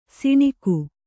♪ siṇiku